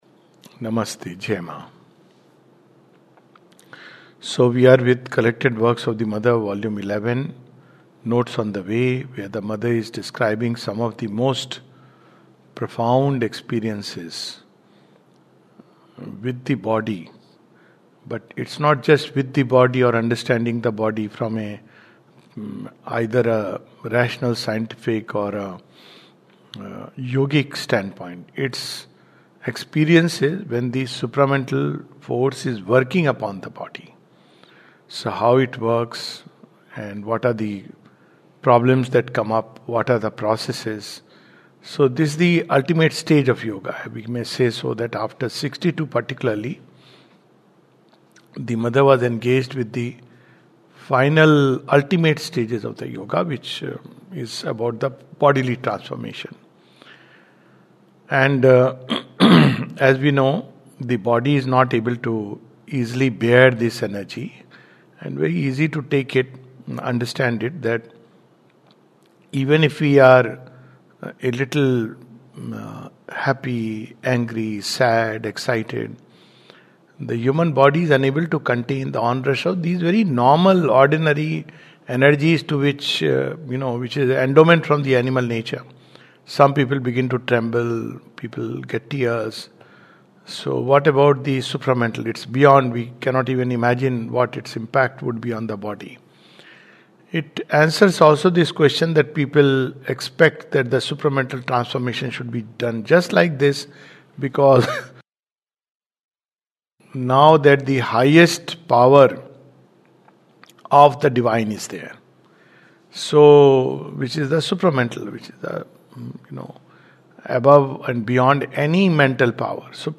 This talk takes up some of the conversations of the Mother on Transfer of Power and Pain from CWM 11, titled Notes on the Way. A talk